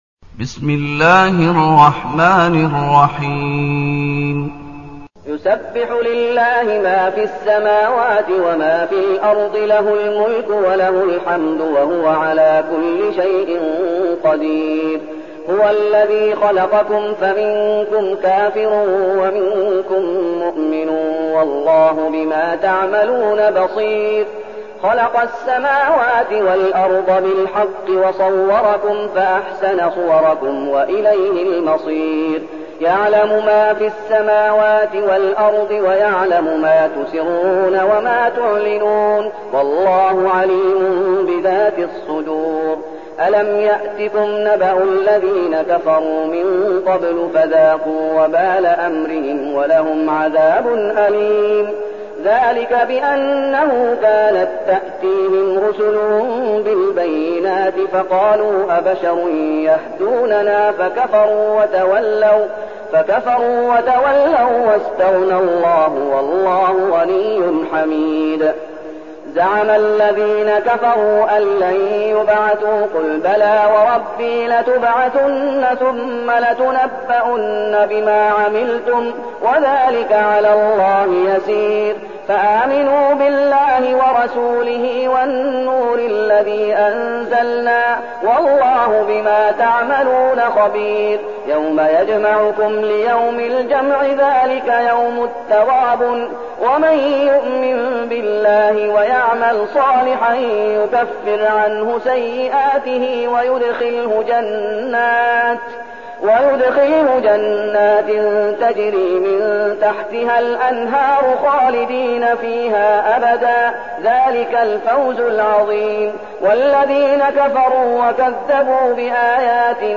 المكان: المسجد النبوي الشيخ: فضيلة الشيخ محمد أيوب فضيلة الشيخ محمد أيوب التغابن